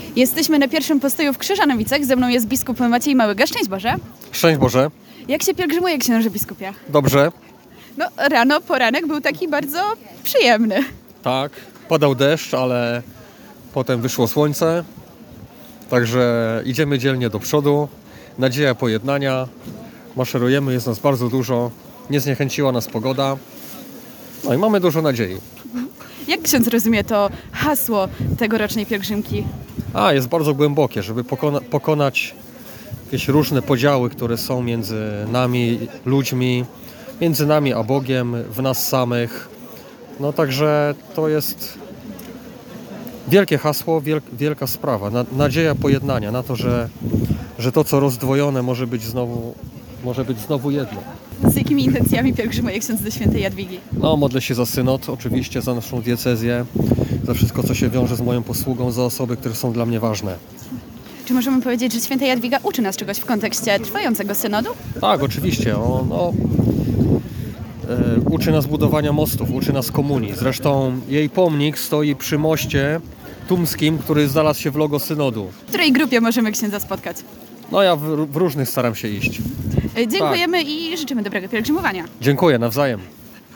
Pierwszy przystanek i rozmowa z Bp Maciejem Małygą: